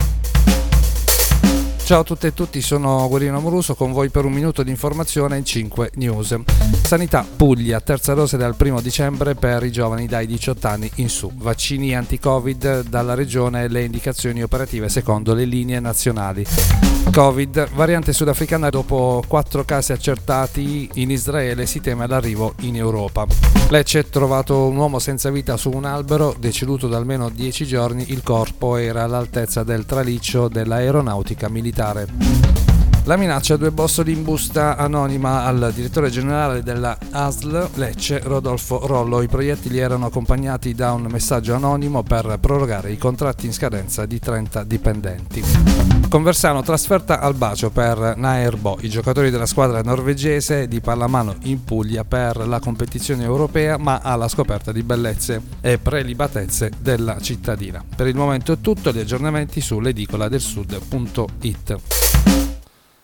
Giornale radio.